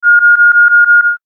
suspend-error.mp3